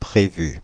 Ääntäminen
Ääntäminen France (Île-de-France): IPA: /pʁe.vy/ Haettu sana löytyi näillä lähdekielillä: ranska Käännöksiä ei löytynyt valitulle kohdekielelle.